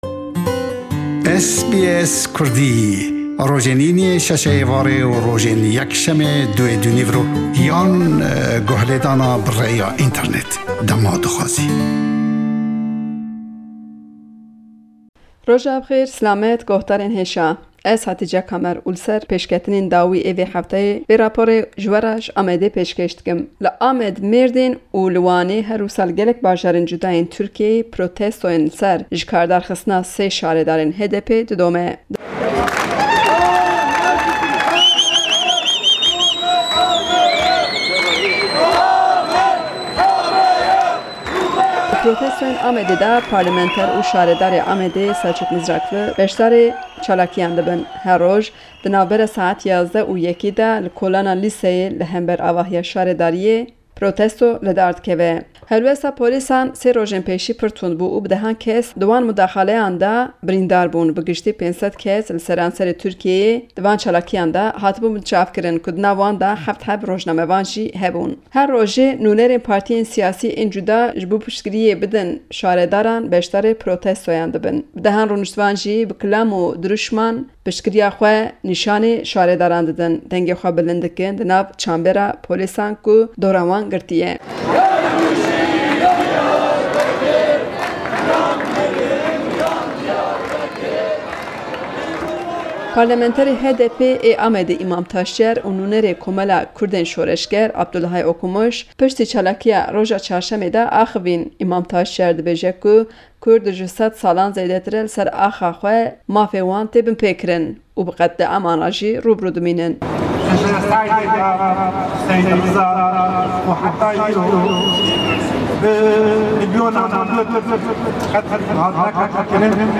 Herweha le em raporte çalakîyekan sebaret be sall-yadî hêrişî terrorîstî sallî 2015 le Amed û çend hewallî dîke...